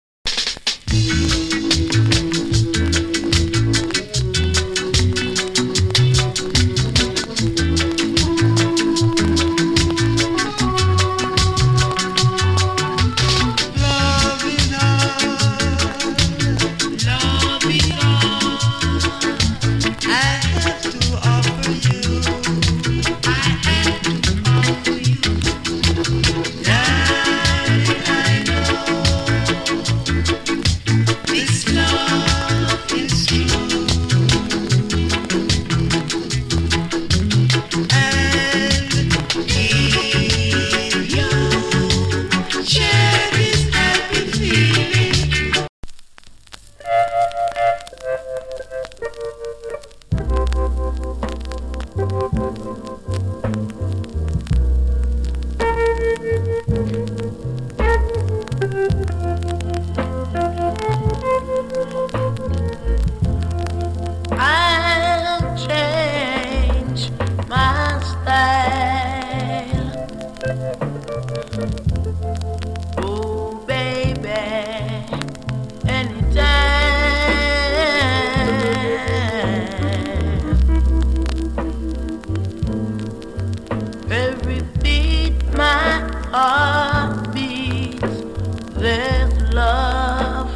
チリ、パチノイズ有り。
チリ、ジリノイズ少し有り。
NICE VOCAL
＋ BALLAD. A-SIDE キズが多いですが、ノイズは少なめです。